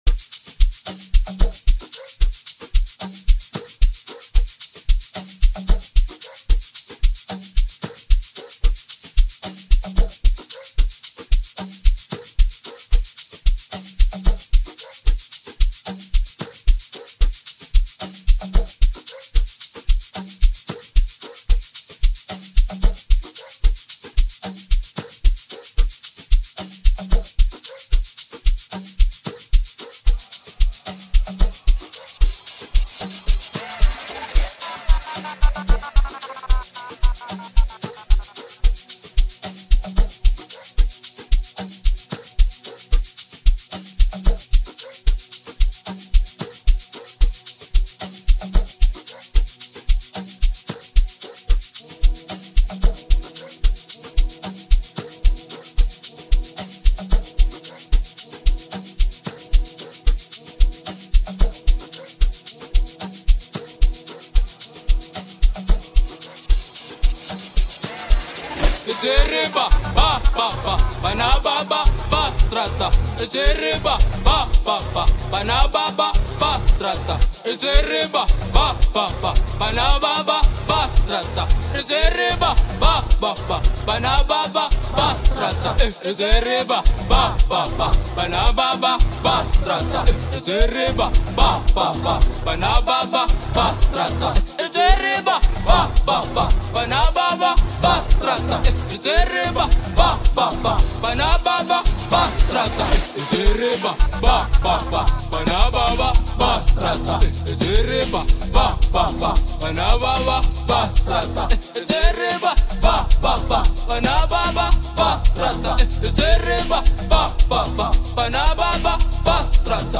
Genre:Amapiano